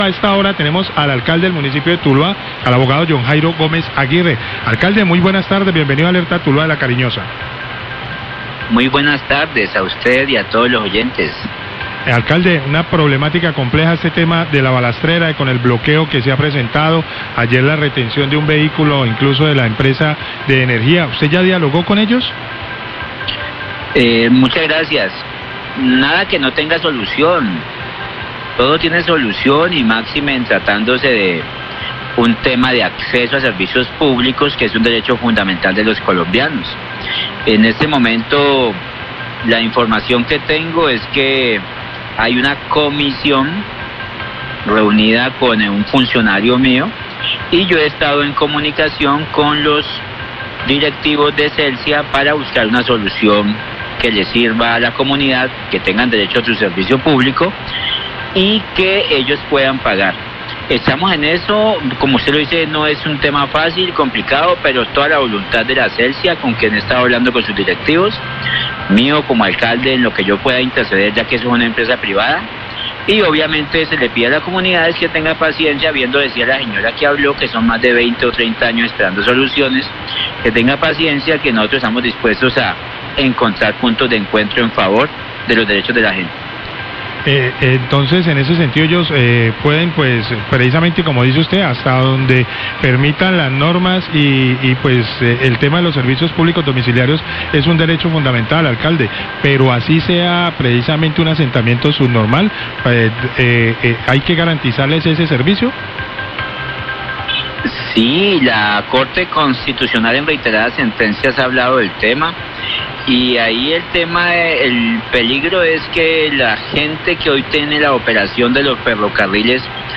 Alcalde Tuluá habla sobre situación en La Balastrera y la problemática por atención de daños en zona rural
Radio